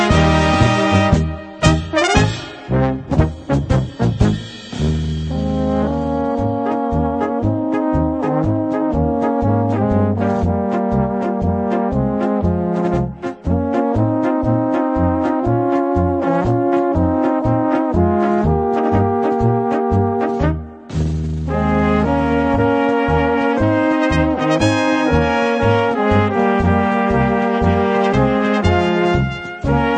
Gattung: Polka für kleine Besetzung
Besetzung: Kleine Blasmusik-Besetzung